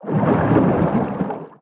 Sfx_creature_chelicerate_swim_fast_01.ogg